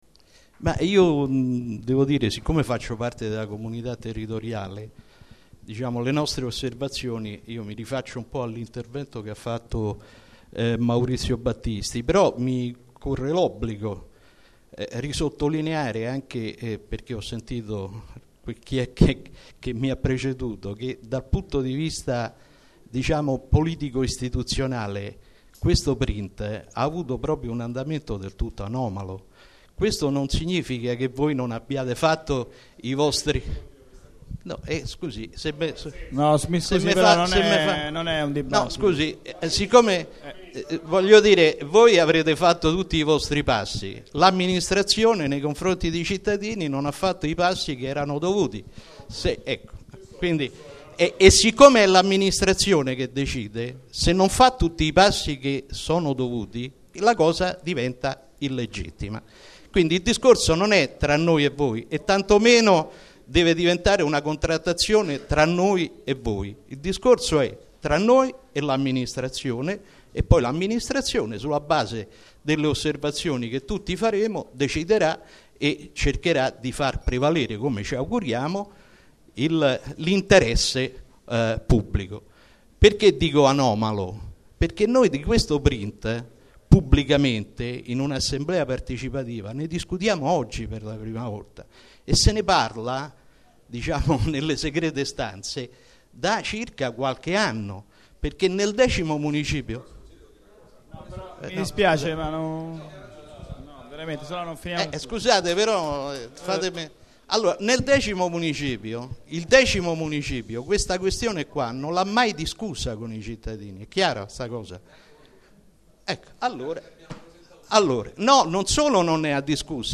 Registrazione integrale dell'incontro svoltosi il 15 luglio 2014 presso la Sala Rossa del Municipio VII, in Piazza di Cinecittà, 11